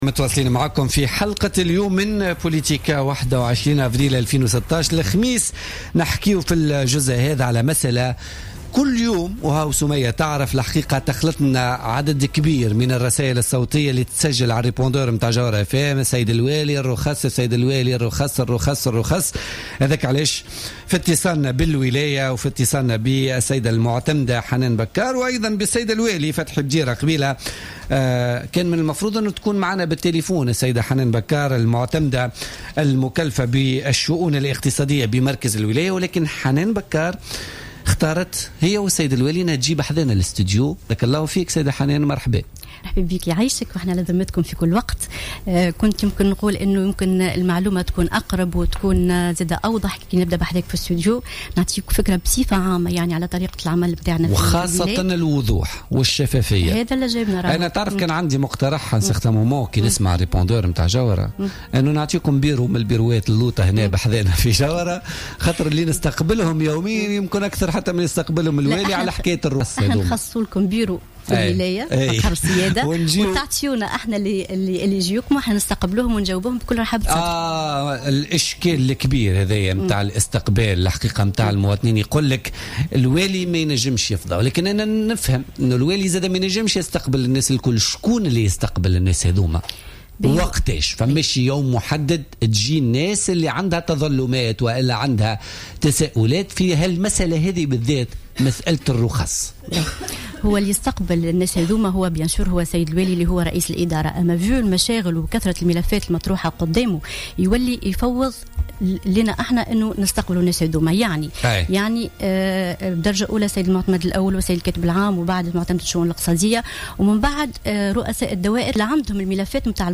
كشفت حنان بكار المعتمد المكلف بالشؤون الاقتصادية بولاية سوسة ضيفة برنامج بوليتكا لليوم الخميس 21 أفريل 2016 أنه تمت دراسة حوالي 35 رخصة جديدة للنقل الجماعي سيتم إسنادها على خطوط موجودة في الجهة أو خطوط تعاني من نقص.